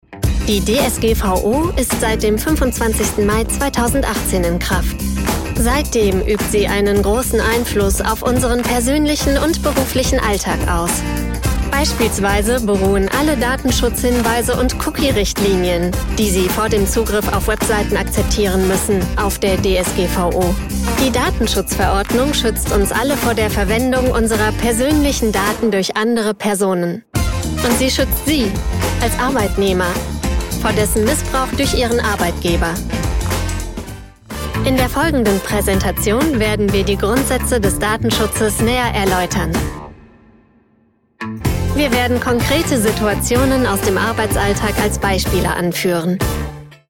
Young, Accessible, Versatile, Warm, Soft
Corporate